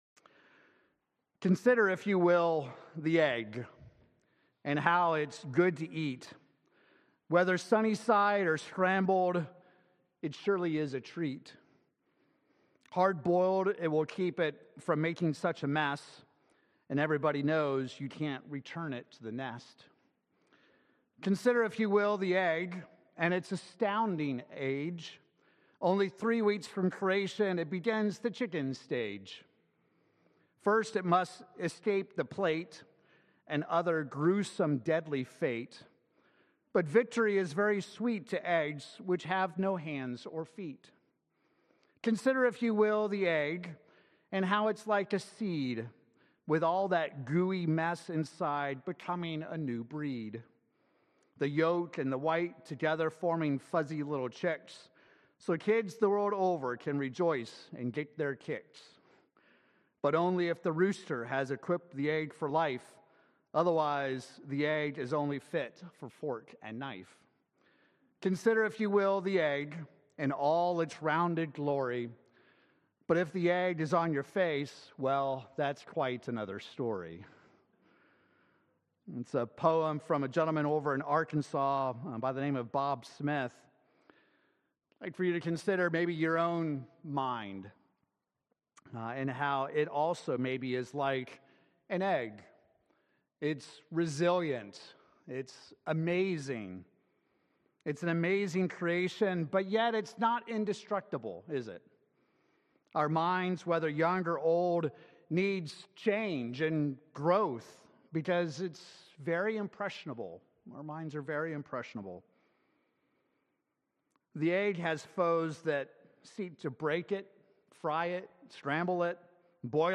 He seeks to desensitize you from the environment around you. In this sermon, let's consider how we can live in this world, but yet not be part of it.